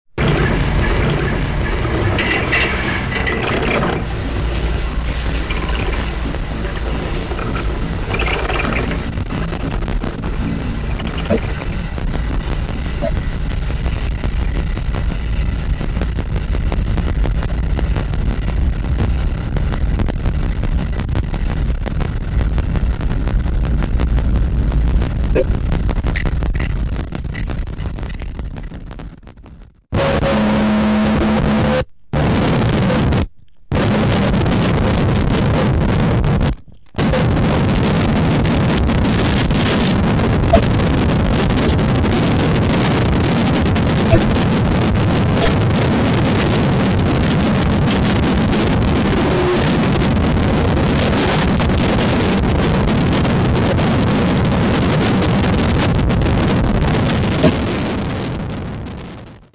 [Abstract, Drone, Noise]
довольно резкого и экстремального фидбэк-нойза